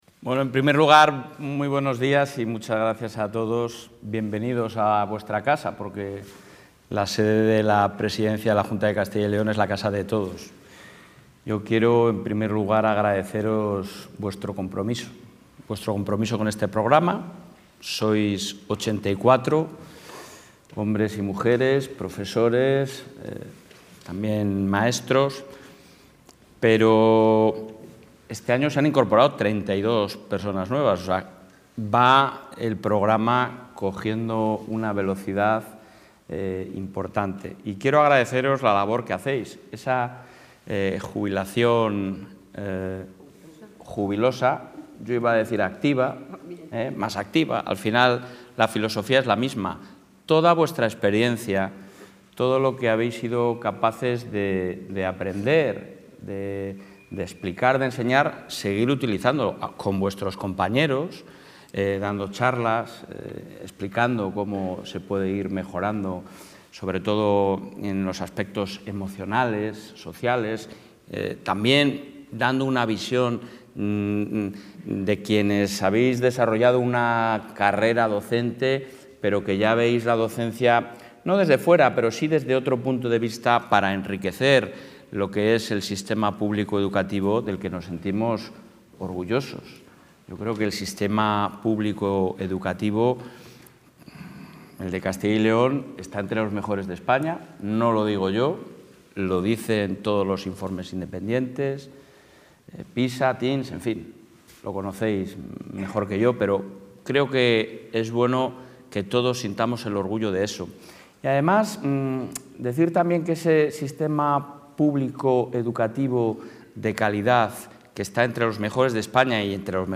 En la recepción a Profesores y Maestros Honoríficos en la Junta de Castilla y León, el presidente de la Junta de Castilla y...
Intervención del presidente.